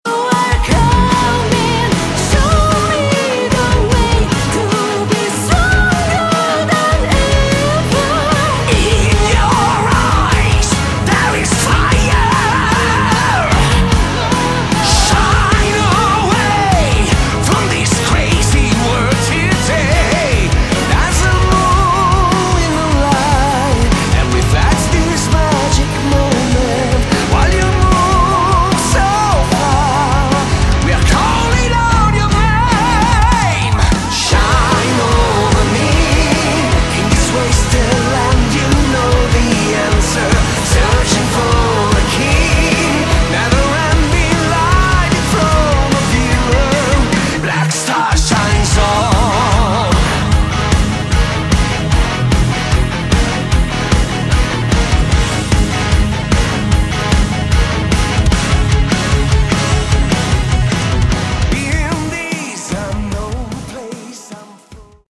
Category: Melodic Metal
lead vocals
guitars, keyboards
bass
drums